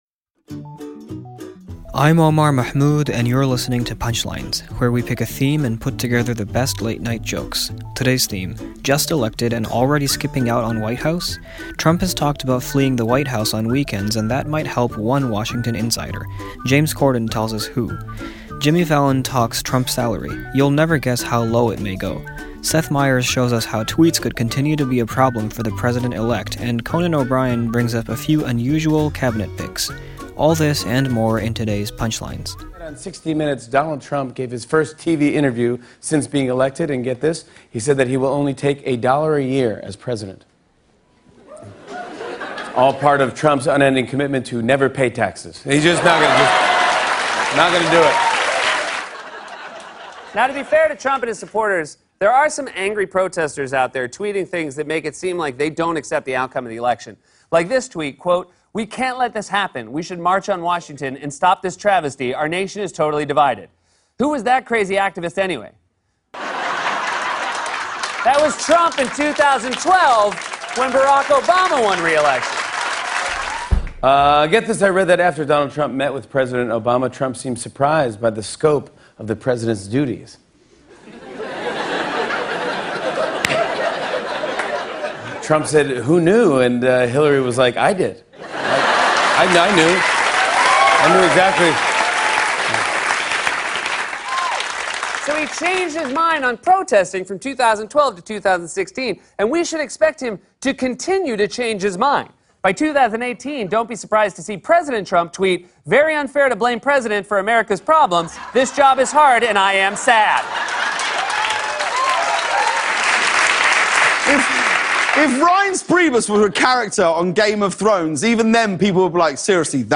The late-night comics on Trump's recent '60 Minutes' interview and possible White House plans.